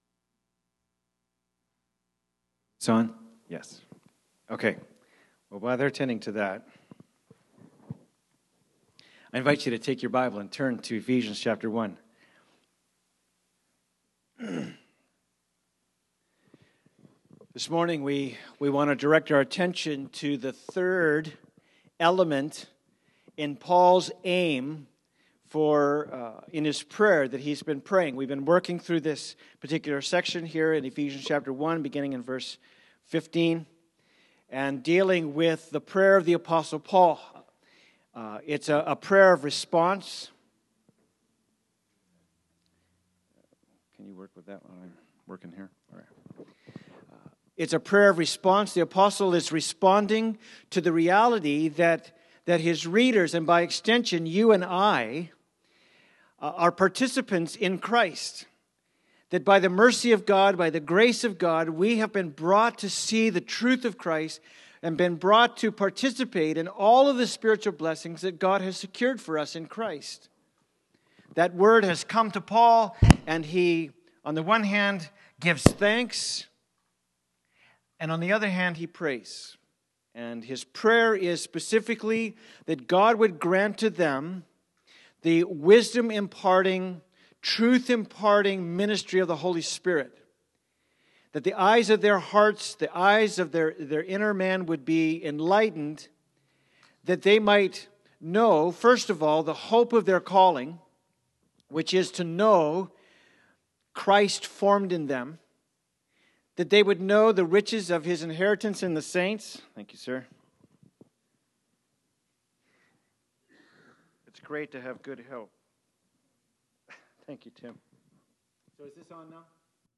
Ephesians 1:19-21 Service Type: Sunday Service « The Just Shall Live by Faith “Unceasing Prayer